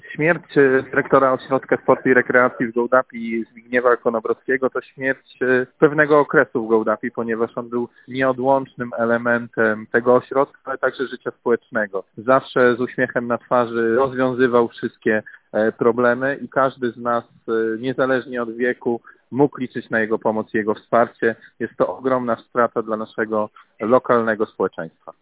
– To ogromna strata dla lokalnego świata sportu, mówi Radiu 5 Konrad Kazaniecki, burmistrz Gołdapi .